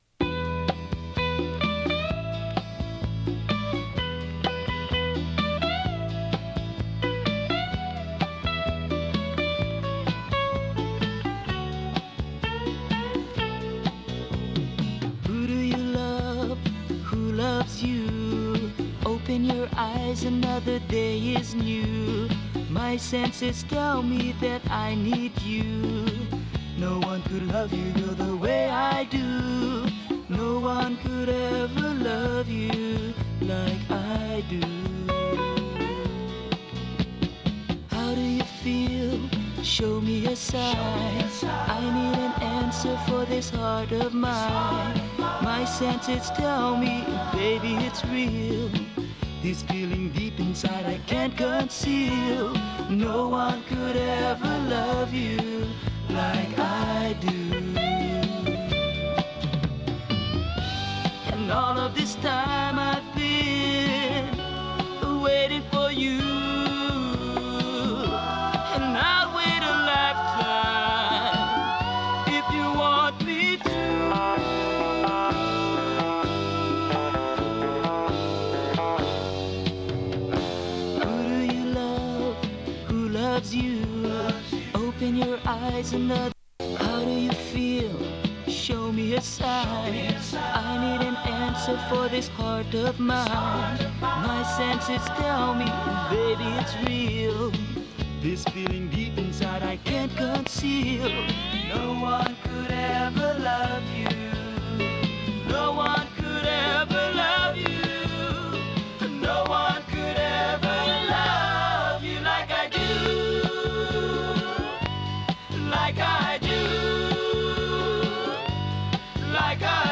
R&B、ソウル
音の薄い部分で時折軽いパチ・ノイズ。